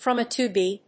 from Á to [N16-A152]
発音